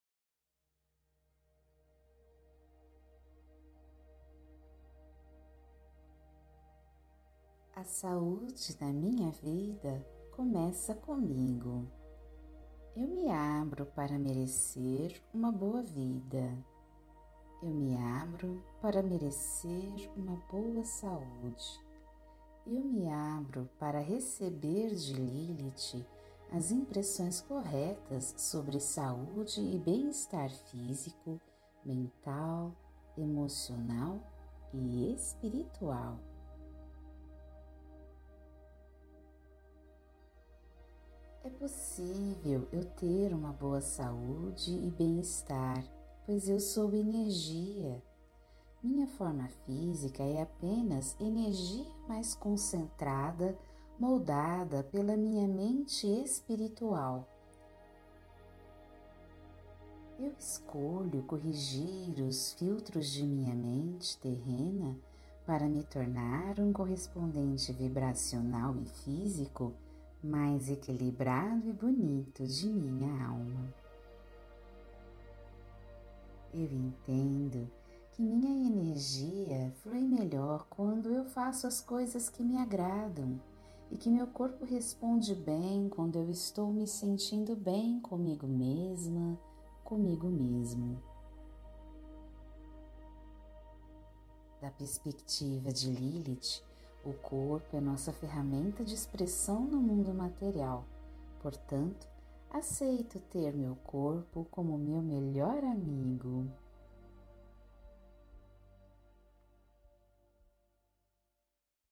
Esta meditação foi projetada para lhe transmitir e alinhar as crenças que Lilith tem sobre saúde, e a cura do espírito, da mente e do corpo, dentro de você.